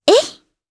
Yuria-Vox_Attack2_jp_b.wav